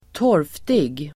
Uttal: [²t'år:ftig]